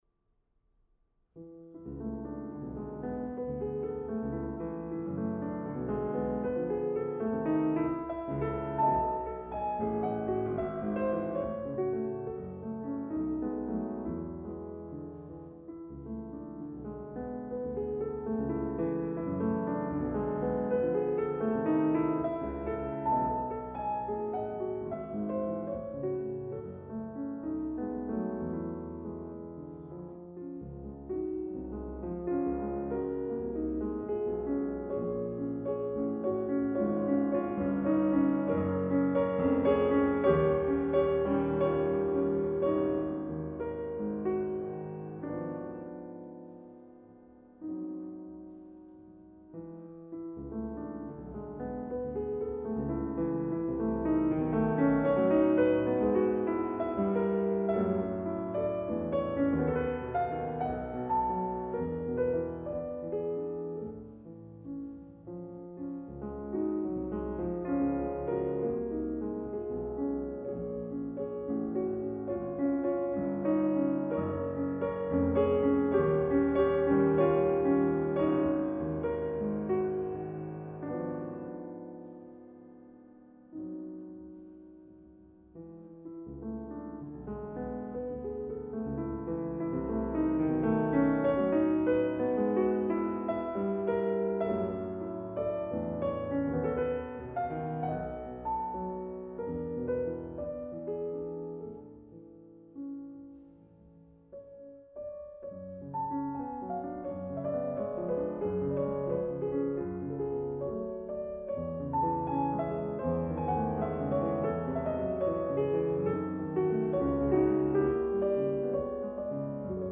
Adam Laloum| Piano | Musicaglotz